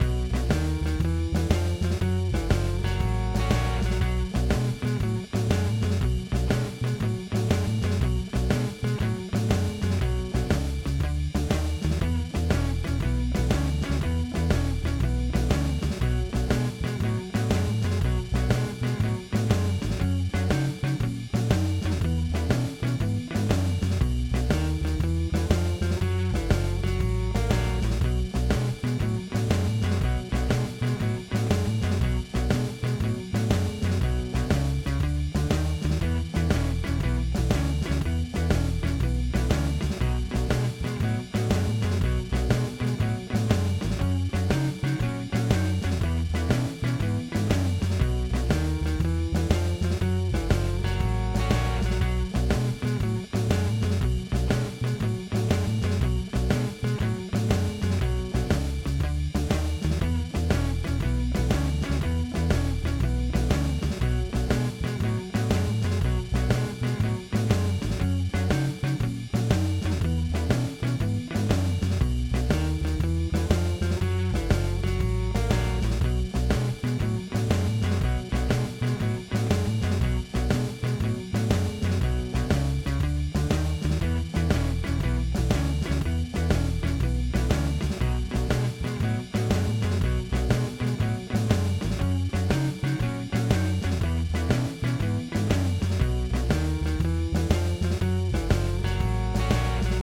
Blues Playalongs
12 BAR BLUES
B Blues (Shuffle)